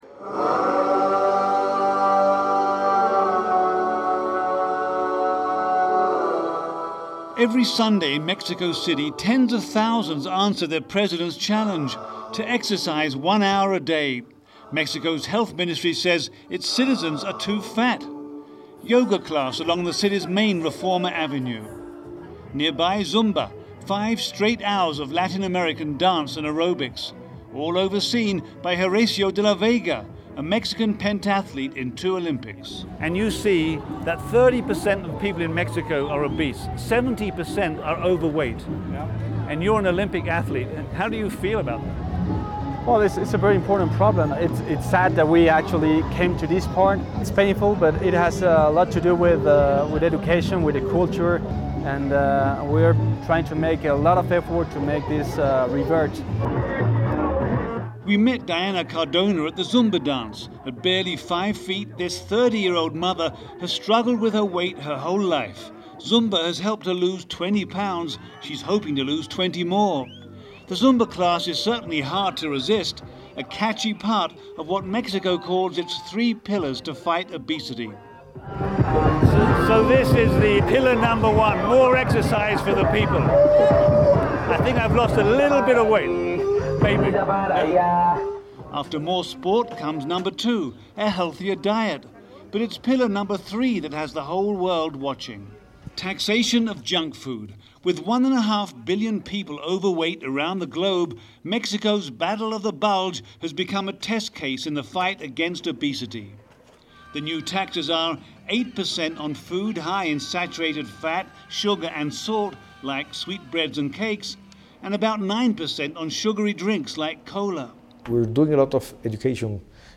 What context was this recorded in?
This report originally aired on February 16, 2014.